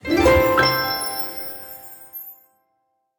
level_win.ogg